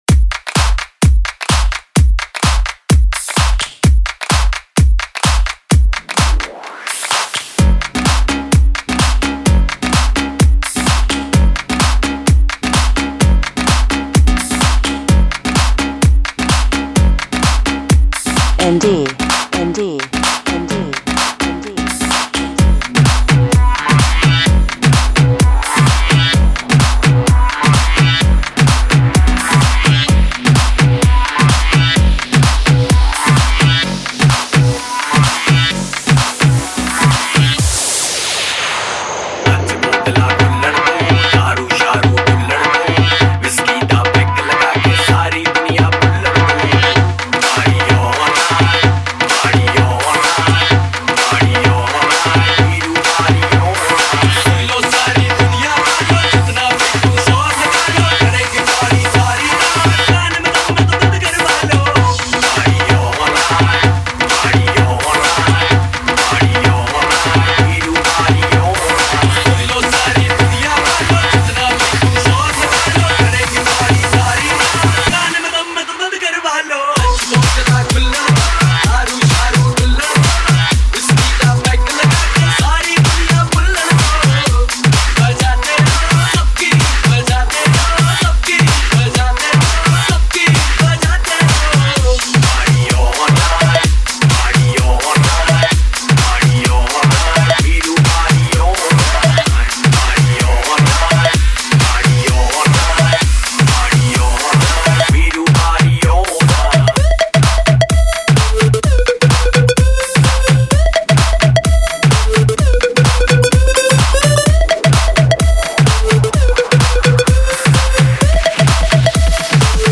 Single Dj Mixes